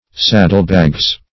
Search Result for " saddlebags" : The Collaborative International Dictionary of English v.0.48: Saddlebags \Sad"dle*bags\, n. pl. Bags, usually of leather, united by straps or a band, formerly much used by horseback riders to carry small articles, one bag hanging on each side.